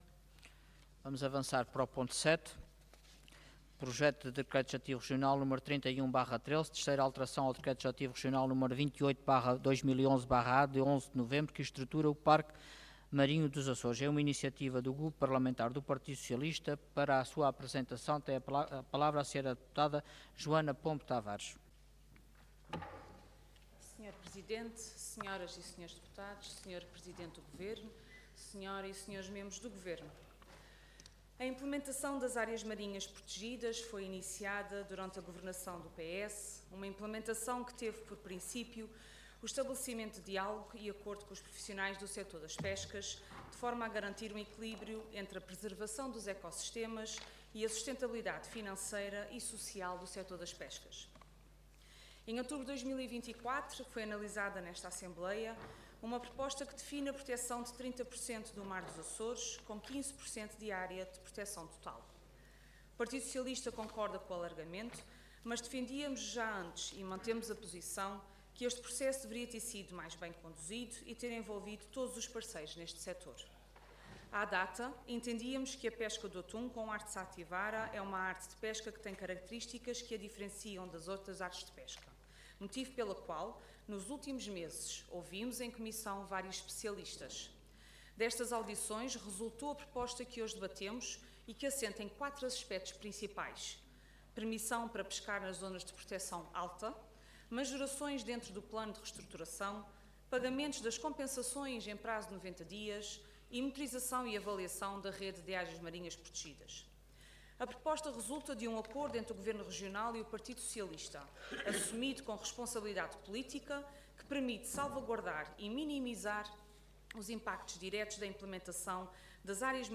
Intervenção
Orador Joana Pombo Tavares Cargo Deputada Entidade PS